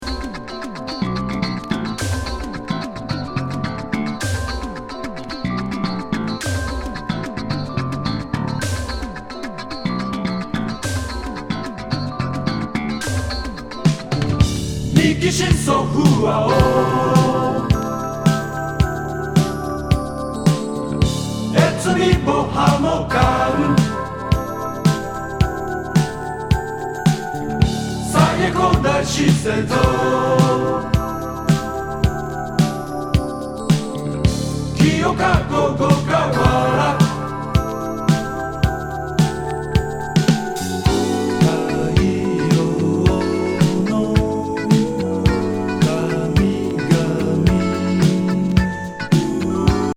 ロンドン録音の85年作。電子ドラム・サンプリングから